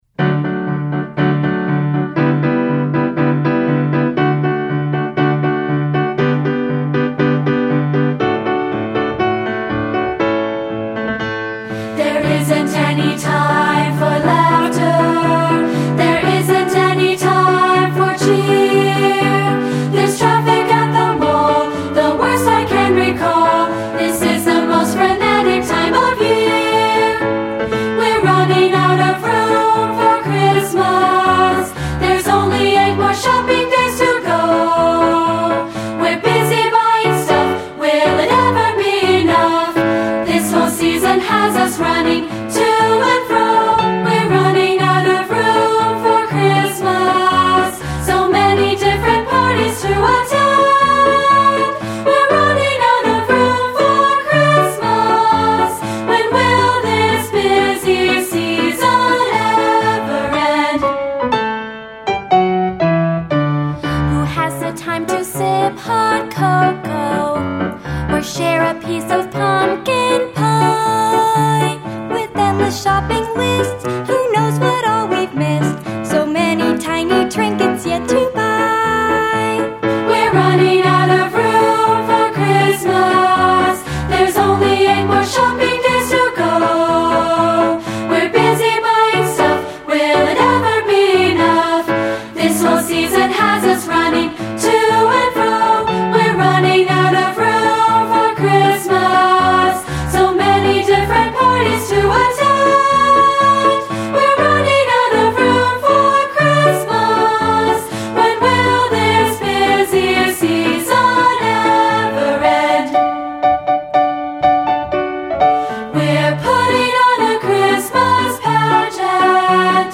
Voicing: Unison|2-Part